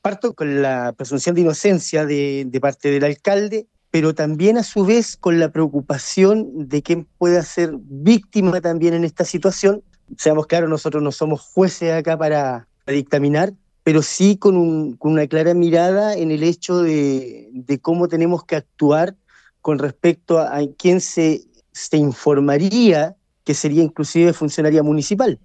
El concejal de Renovación Nacional, Fredy Cartes, recordó que existe la presunción de inocencia para el alcalde, agregando que hay preocupación por la posible víctima.